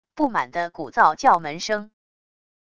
不满的鼓噪叫门声wav音频